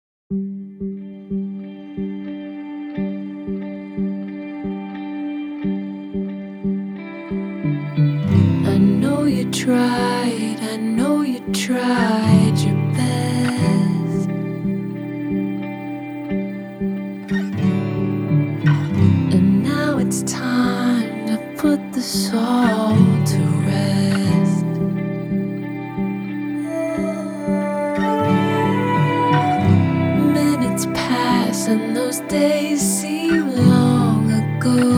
Жанр: Поп музыка / Соундтрэки